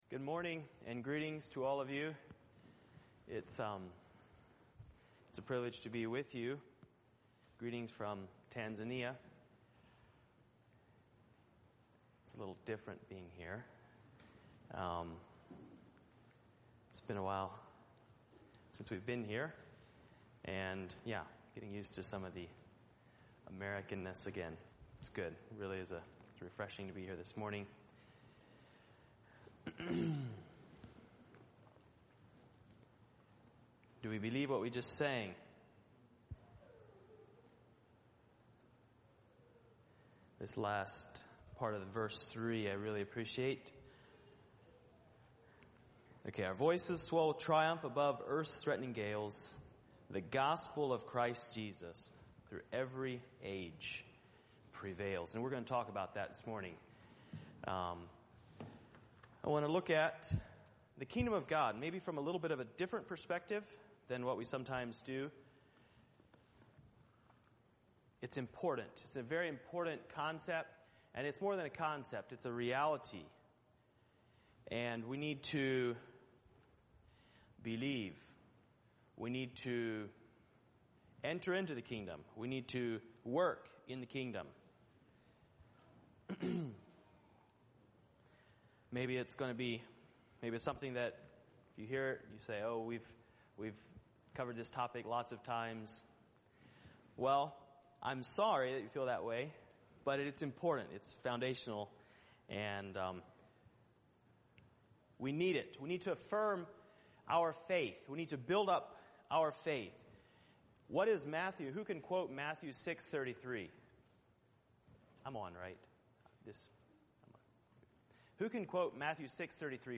Living Hope | Sermon